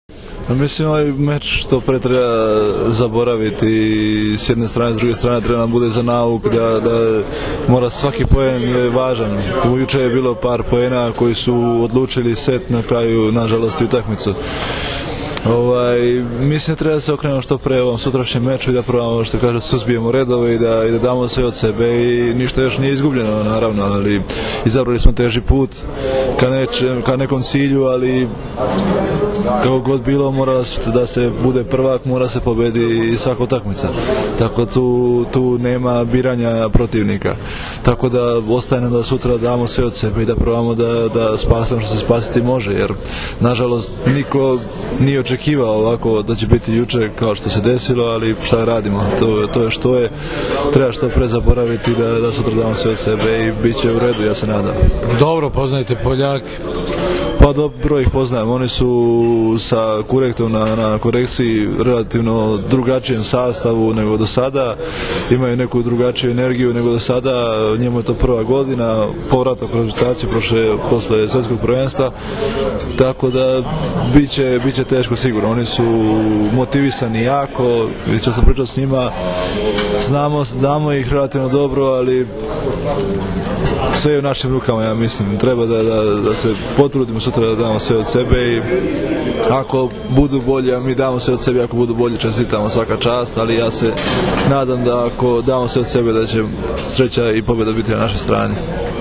IZJAVA MARKA IVOVIĆA